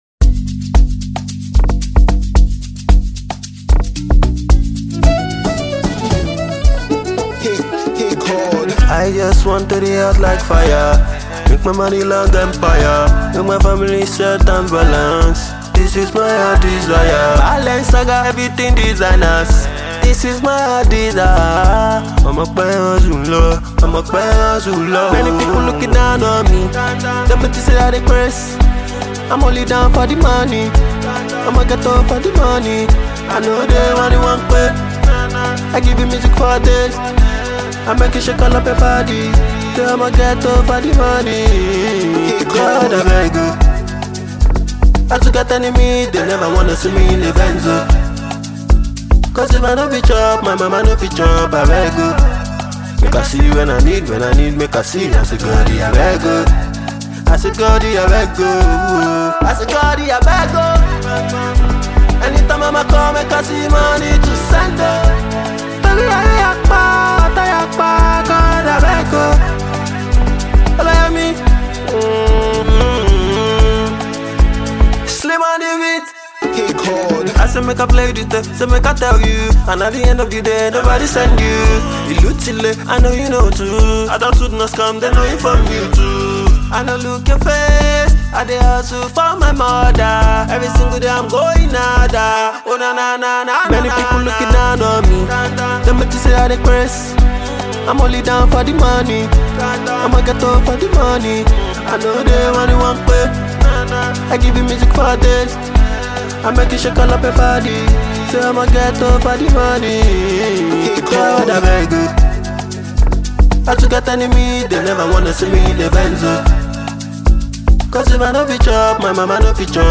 The record is a melodious track with good strings.
sweeping voice